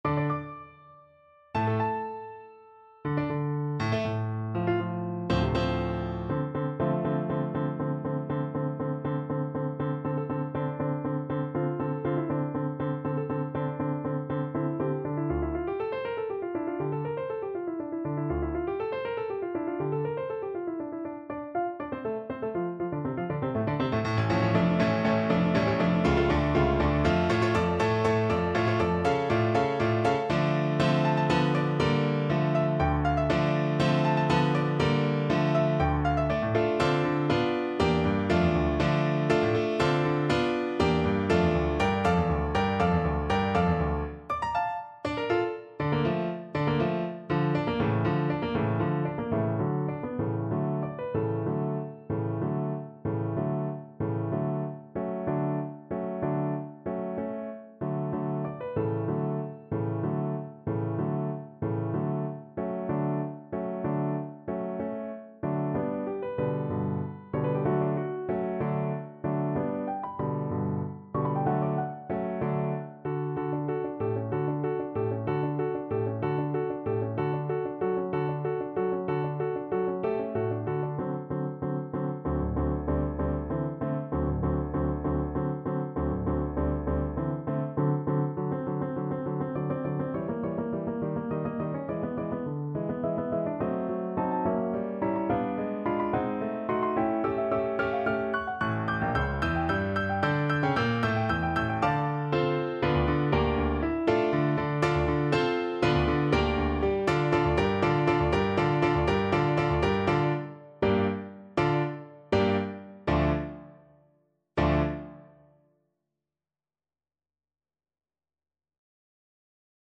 Play (or use space bar on your keyboard) Pause Music Playalong - Piano Accompaniment Playalong Band Accompaniment not yet available reset tempo print settings full screen
D minor (Sounding Pitch) A minor (French Horn in F) (View more D minor Music for French Horn )
Molto vivace .=80
Classical (View more Classical French Horn Music)